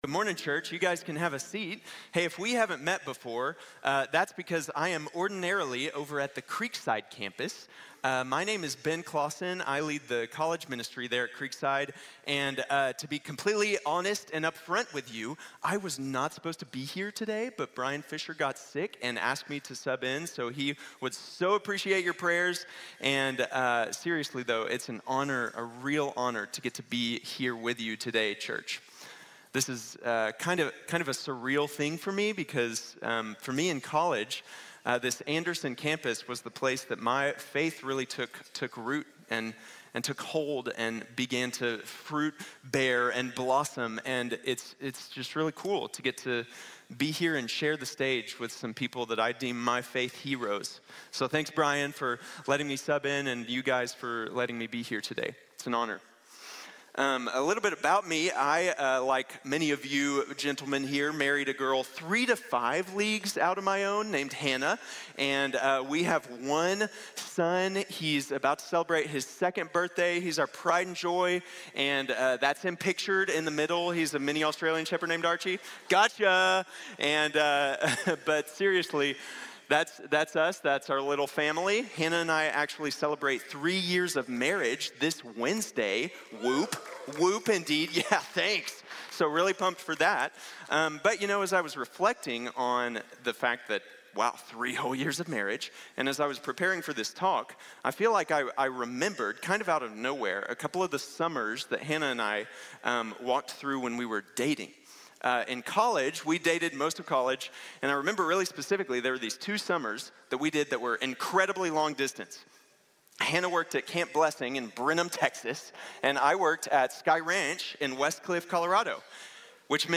God Revealed | Sermon | Grace Bible Church